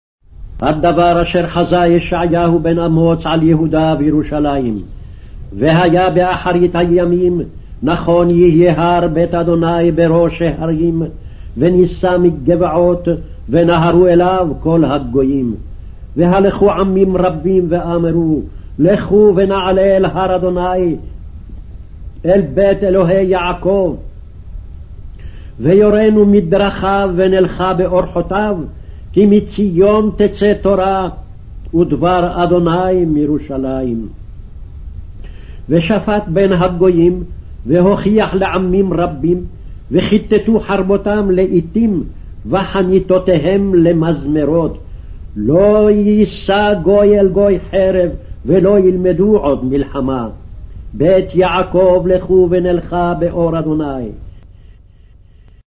02- First Reading- Isaiah.mp3